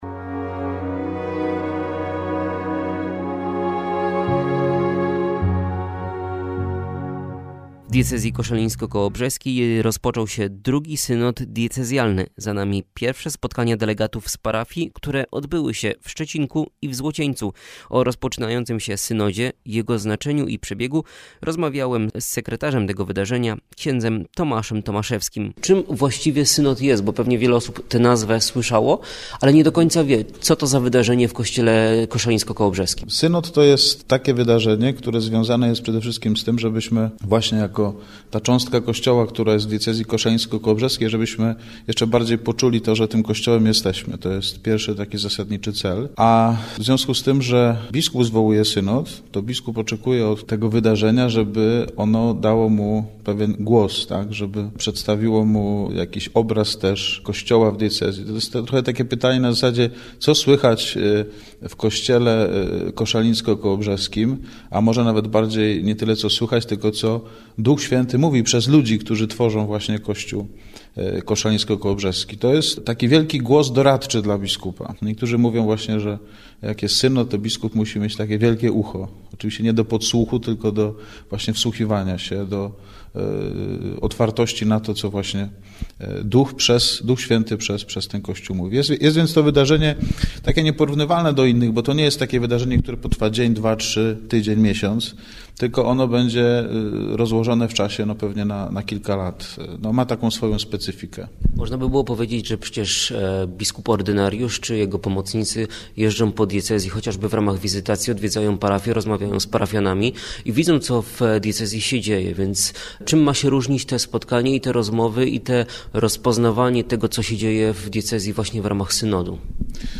Rozmowa na temat synodu